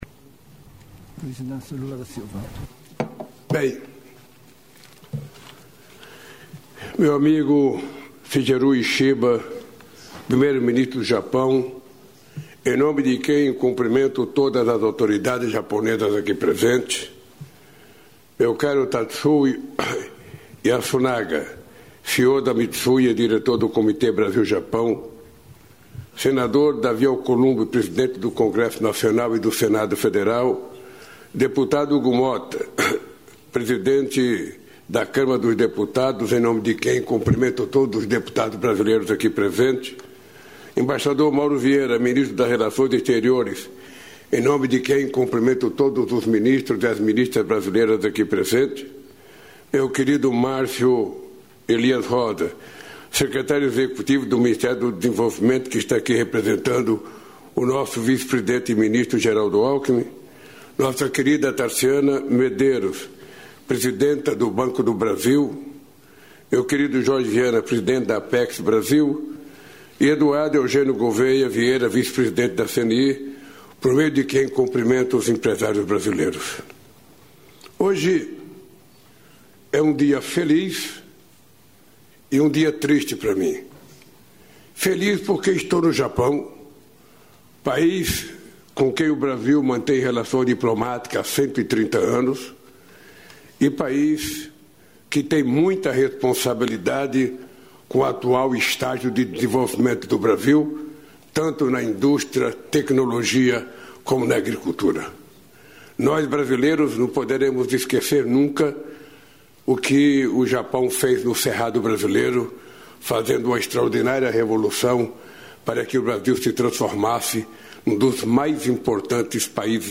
Íntegra do discurso do presidente da República, Luiz Inácio Lula da Silva, no encerramento do Fórum Empresarial Brasil-Japão, em Tóquio (Japão), nesta quarta-feira (26).